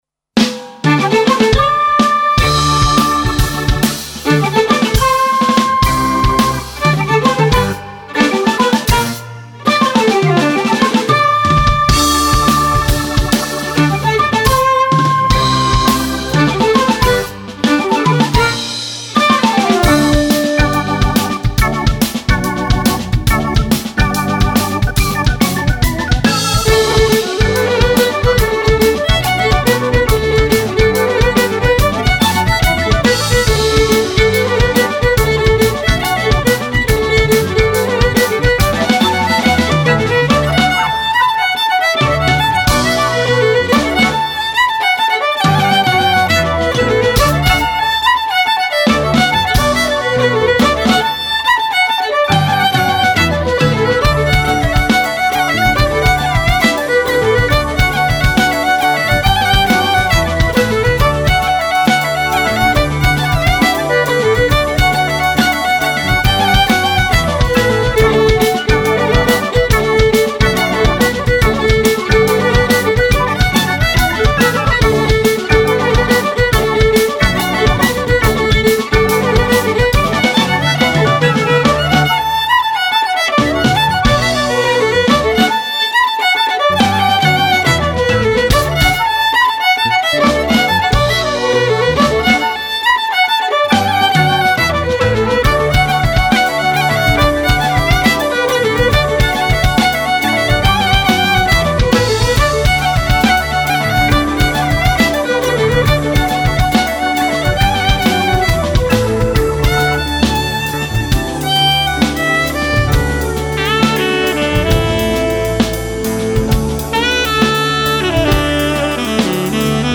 Extraits des musiques enregistrées au studio professionnel MUSIC BDFL